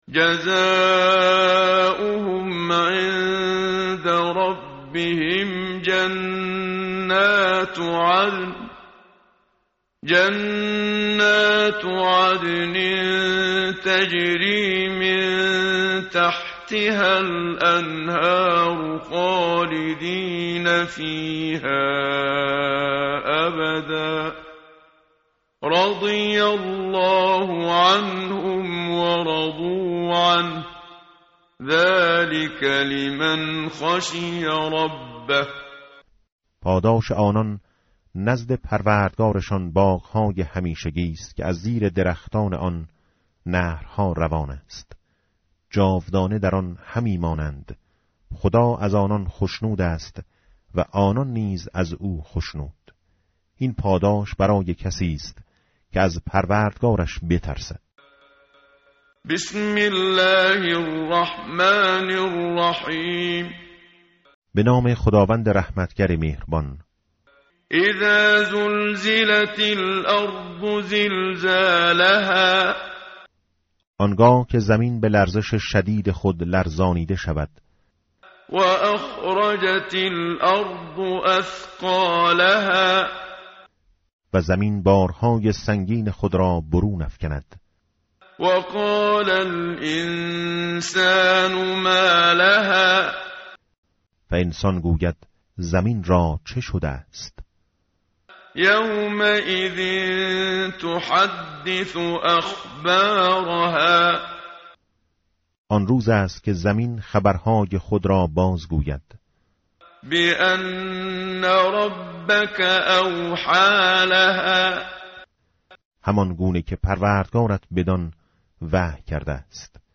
متن قرآن همراه باتلاوت قرآن و ترجمه
tartil_menshavi va tarjome_Page_599.mp3